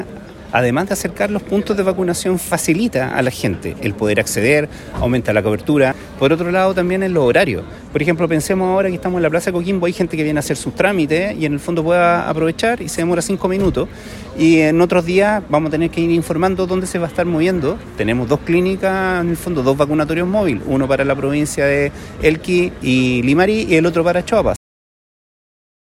Asimismo, el Dr. Gabriel Sanhueza, director (s) del Servicio de Salud Coquimbo, destaca los beneficios de desplegar en el territorio esta estrategia
Doctor-Gabriel-Sanhueza-director-Servicio-de-Salud-Coquimbo.mp3